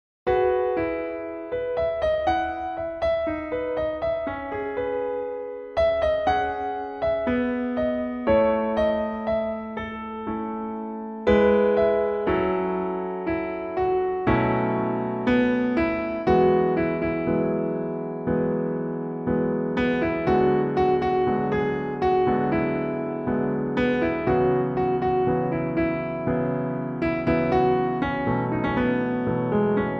• 🎹 Instrument: Piano Solo
• 🎼 Key: E Major
• 🎶 Genre: Pop
elegant piano solo arrangement